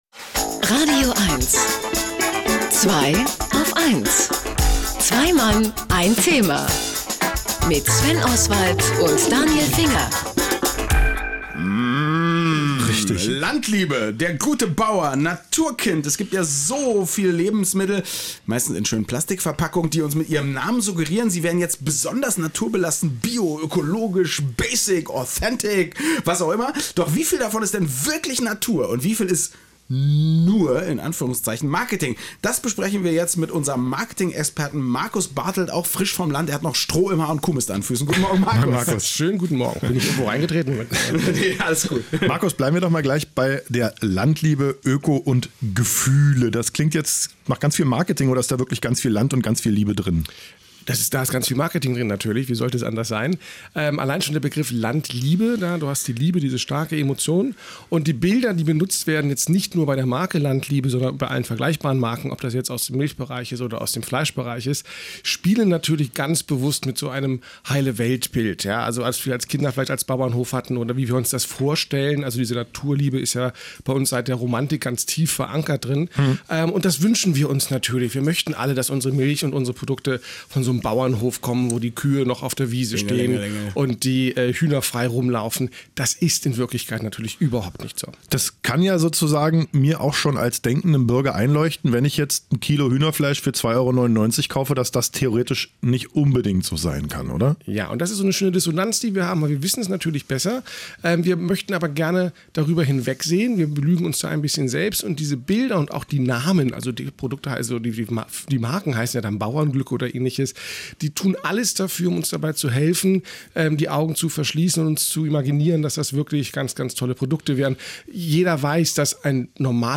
Ich weiß jetzt nicht, ob diese beiden munteren Milchbäuerinnen aus dem wonnigen Wuppertal auf Männersuche sind, aber wenn man sich die Landliebe-Website anschaut, dann sind wir schon mittendrin, denn diesmal ging es im Interview bei „Zweiaufeins“ in „radioeins“ um die Frage, wie das Lebensmittelmarketing mit einer Bilderidylle vom Landleben uns Verbraucher ködert: